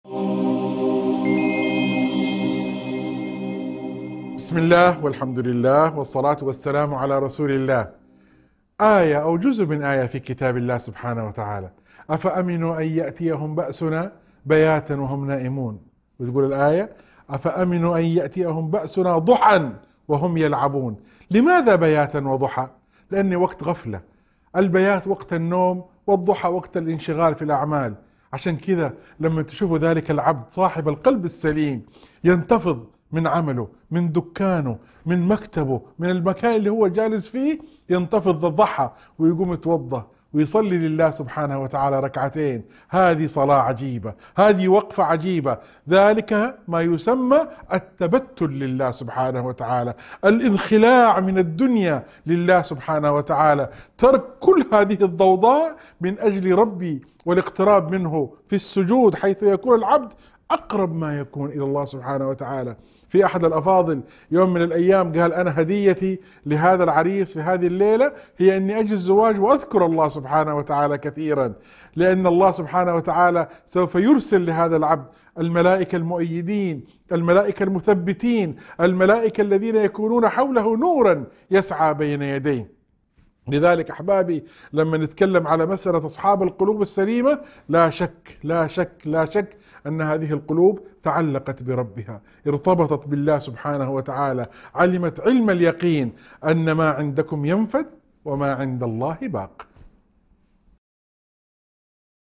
موعظة تركز على أهمية صلاة الضحى كوقت للانخلاع من شواغل الدنيا والتقرب إلى الله. يشرح النص فضل هذه الصلاة وارتباطها بثبات القلب والسكينة، مستشهداً بآيات من القرآن الكريم.